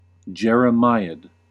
Uttal
Synonymer lament lamentation Uttal US RP: IPA : /ˌdʒɛr.əˈmaɪ.əd/ US: IPA : /ˌdʒɛr.əˈmaɪ.əd/ Ordet hittades på dessa språk: engelska Ingen översättning hittades i den valda målspråket. Definitioner Substantiv A long speech or prose work that bitterly laments the state of society and its morals , and often contains a prophecy of its coming downfall .